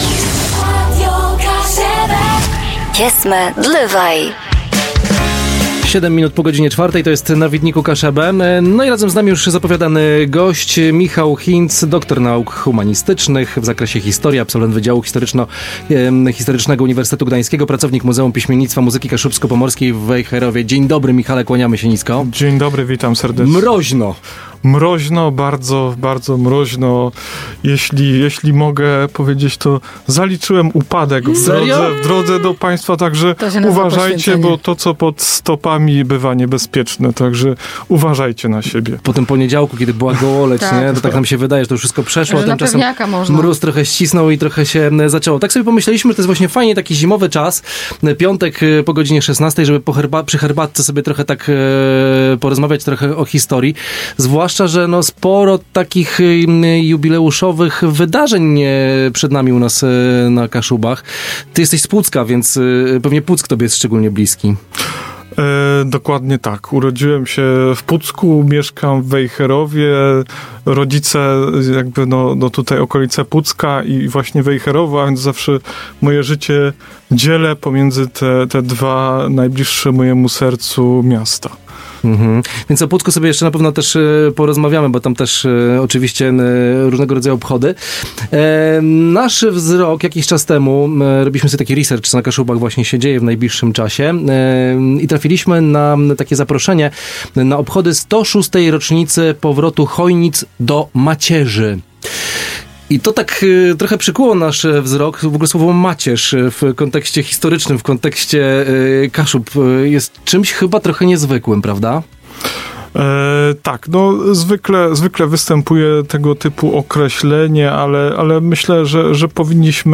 Głównym tematem rozmowy była 106. rocznica powrotu Chojnic w granice państwa polskiego.